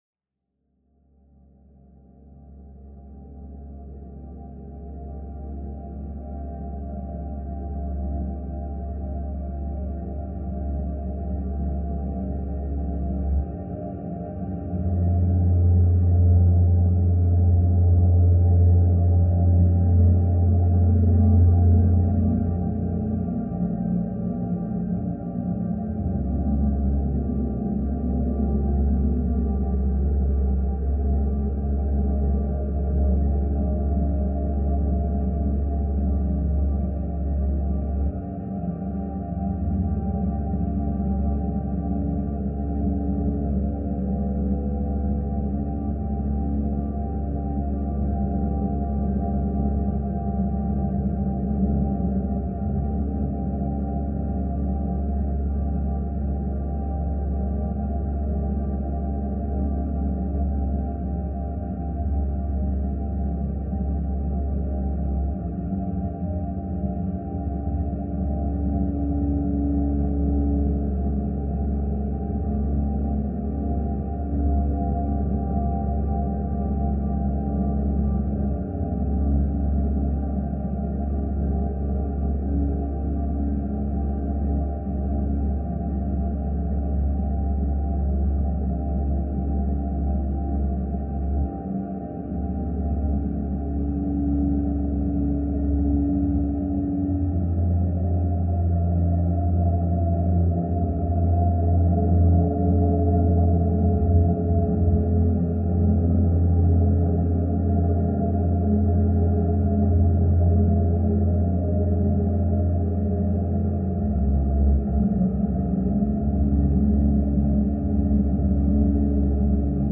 laptop, live electronics, real-time sampling, Max-Msp